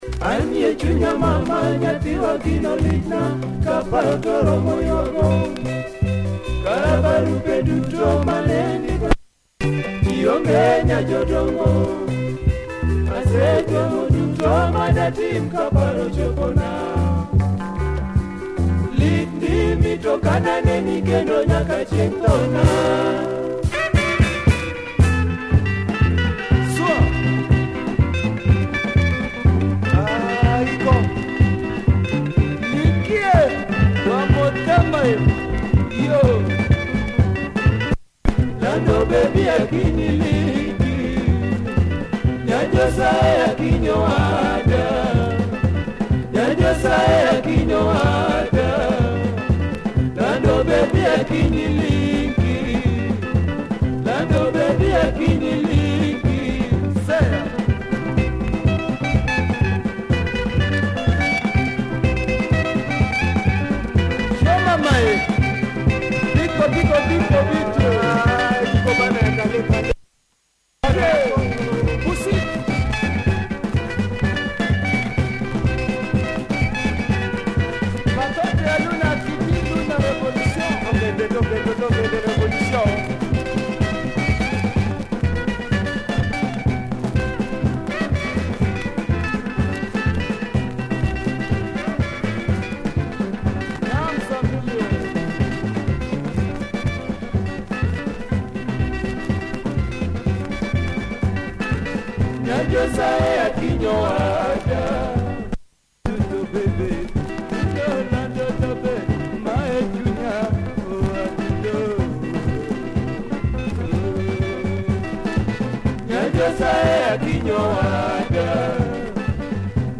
Nice Luo rumba mode here, great drive